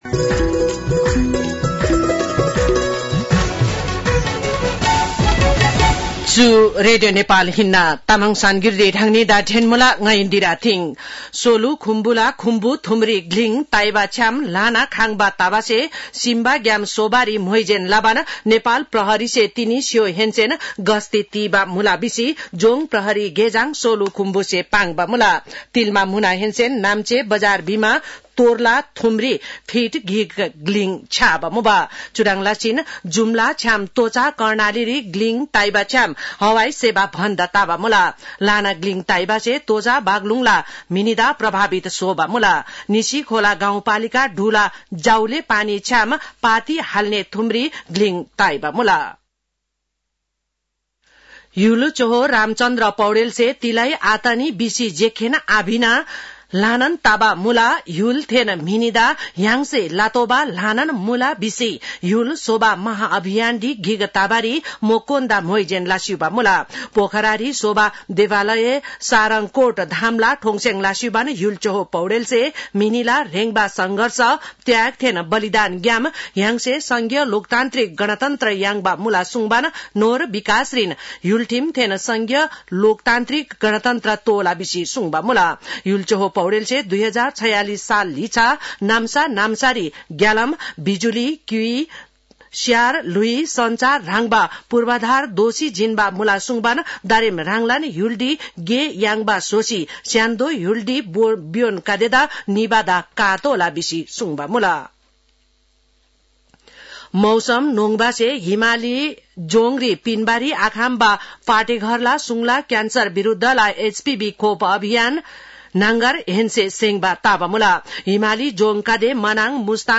तामाङ भाषाको समाचार : १८ फागुन , २०८१